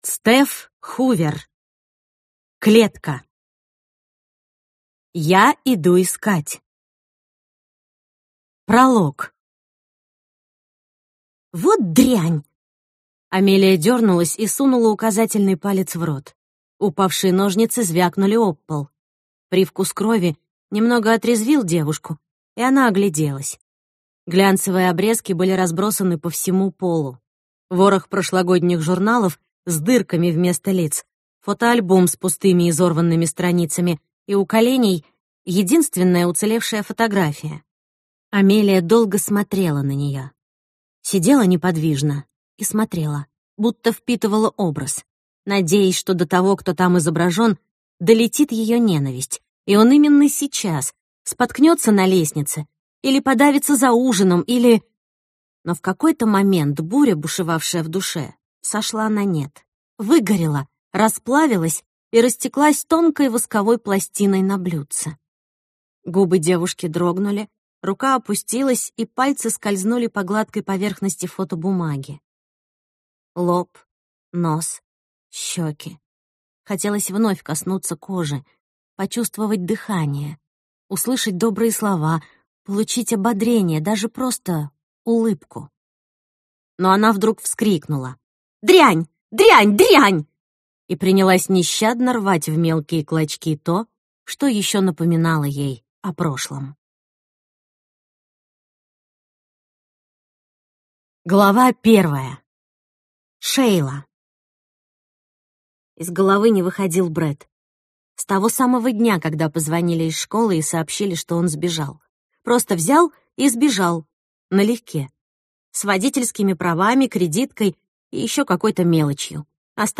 Аудиокнига Клетка | Библиотека аудиокниг